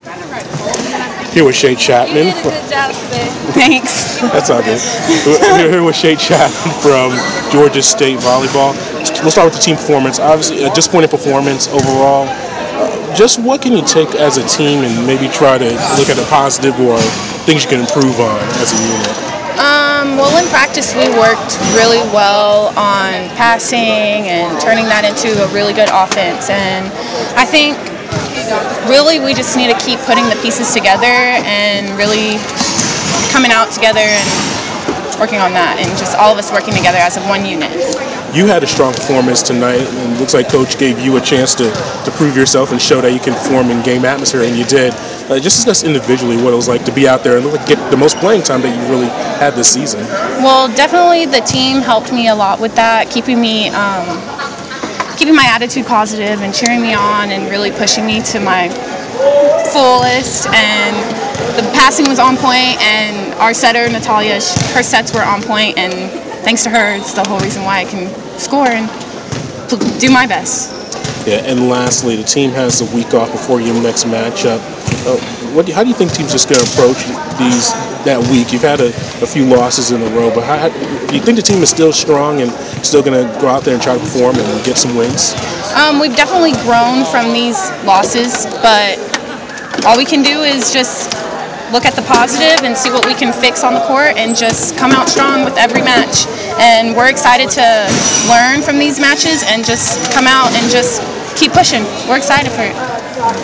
Postmatch interview